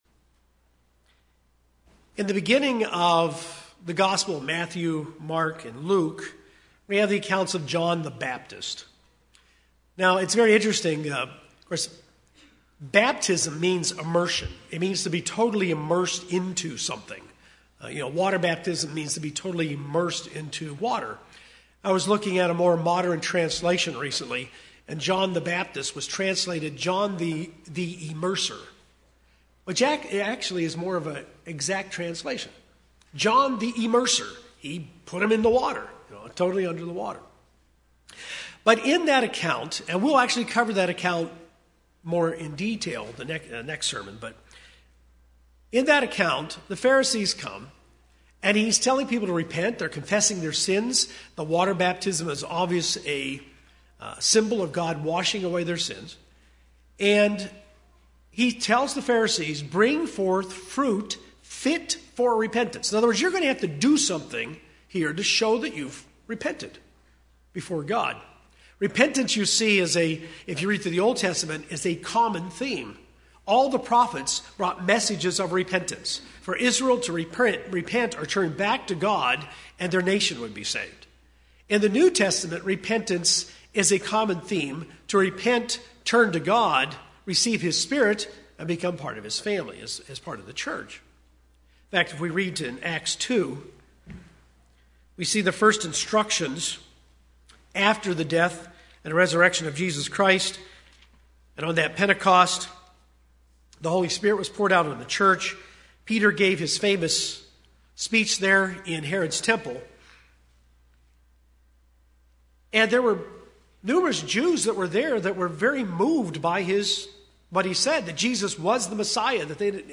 How is God involved in your repentance? This is the second sermon in a series covering the fundamental teachings of Hebrews 6.